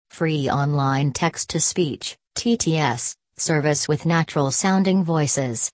Convert Text To Speech
• It offers natural-sounding voices for text to speech conversion.